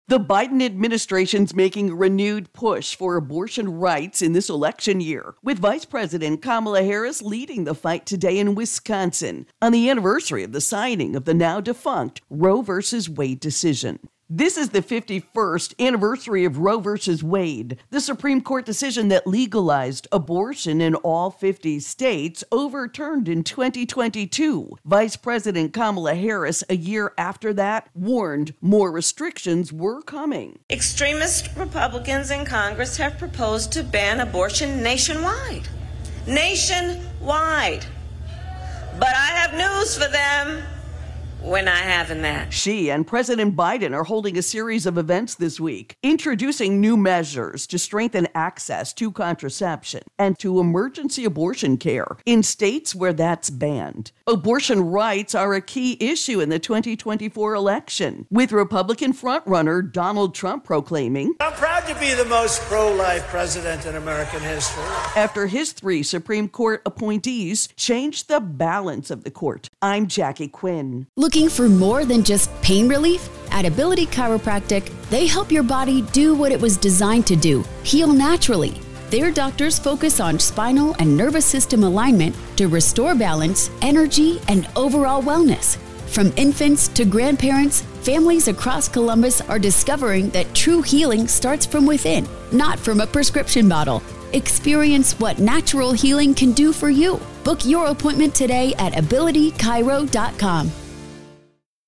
reports on Election 2024 Biden Harris Abortion.